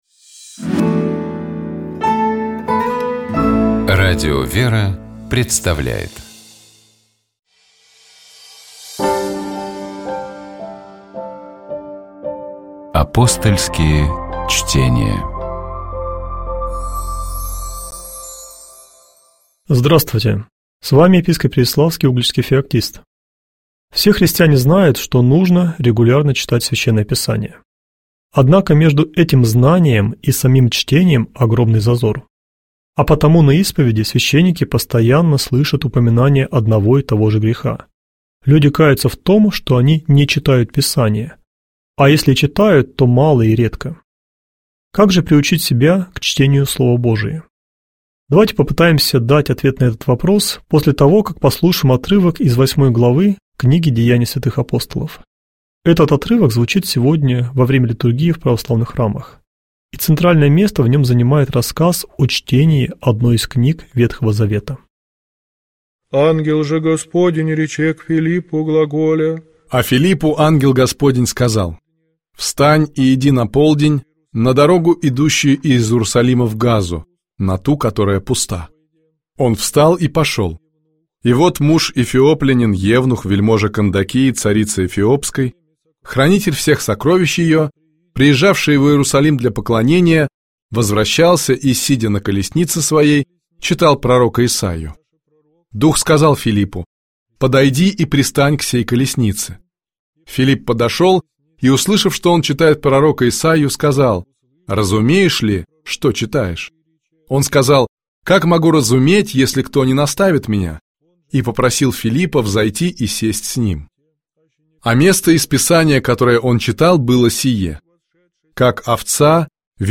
В программе Апостольские чтения можно услышать толкование из новозаветного чтения, которое звучит в этот день в Православных храмах.